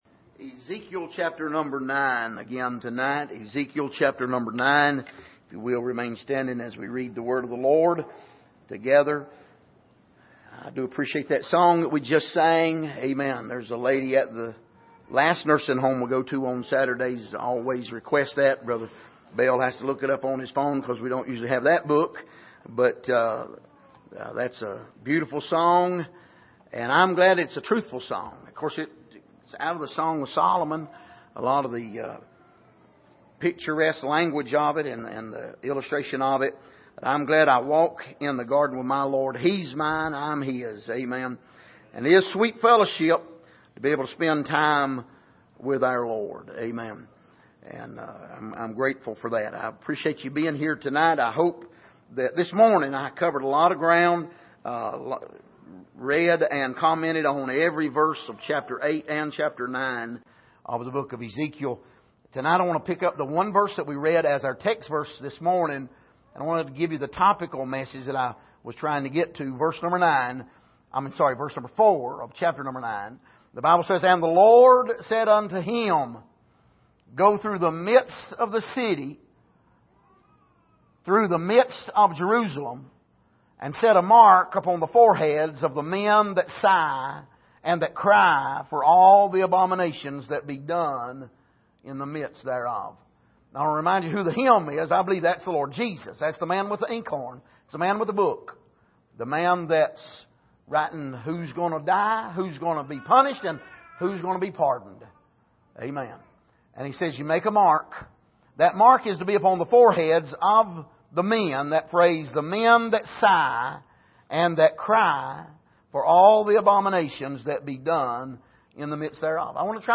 Passage: Ezekiel 9:4 Service: Sunday Evening